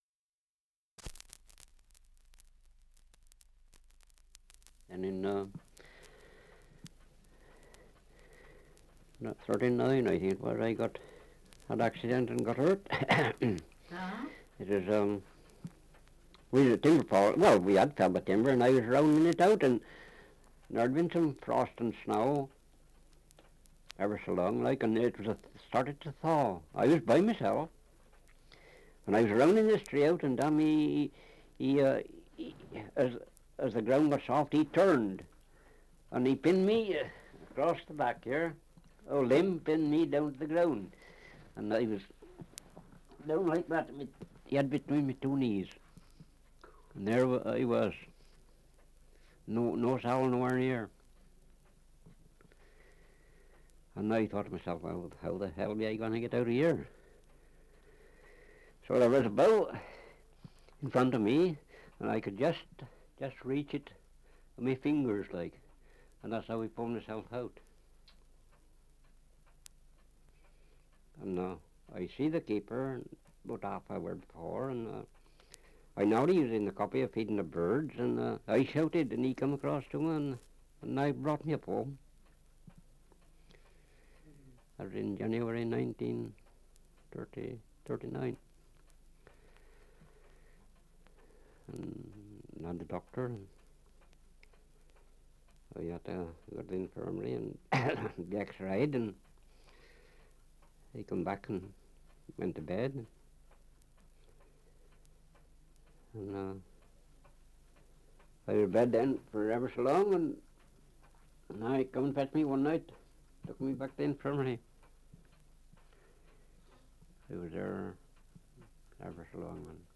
Survey of English Dialects recording in Clifton upon Teme, Worcestershire
78 r.p.m., cellulose nitrate on aluminium